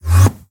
teleport_back.ogg